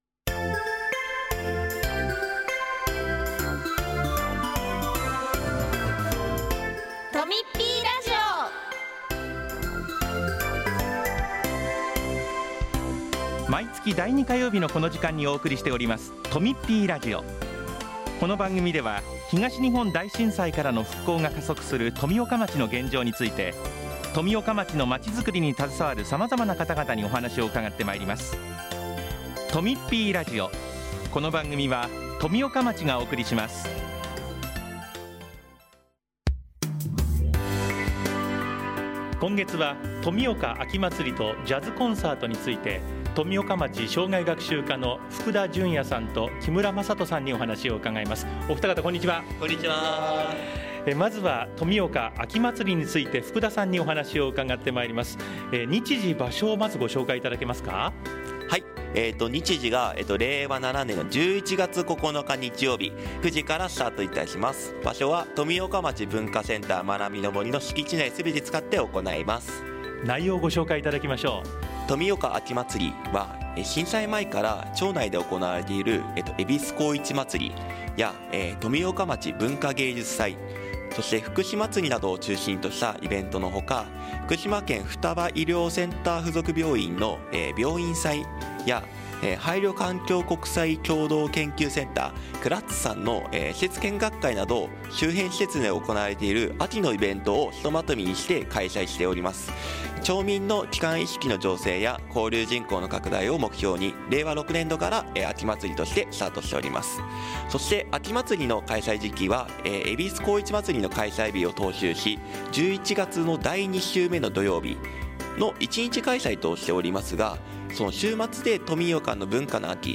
ラジオ福島